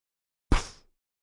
Download Poof sound effect for free.
Poof